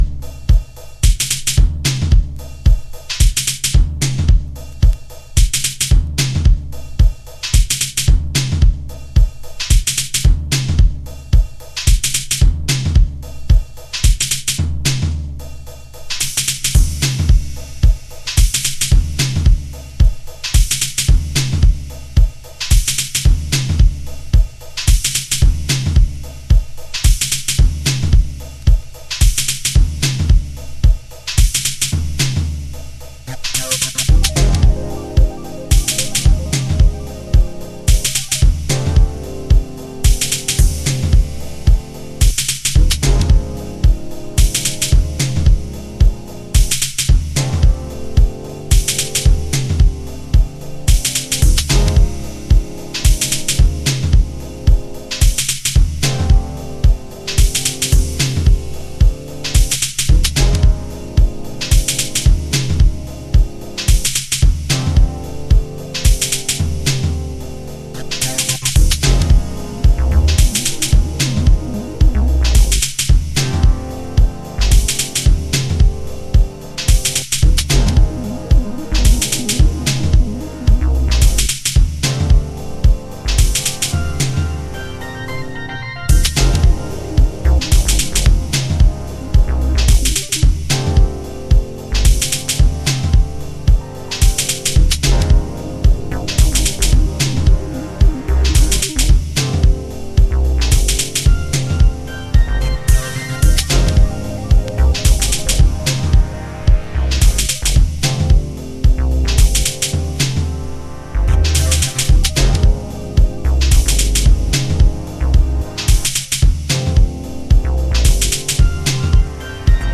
TOP > Detroit House / Techno > VARIOUS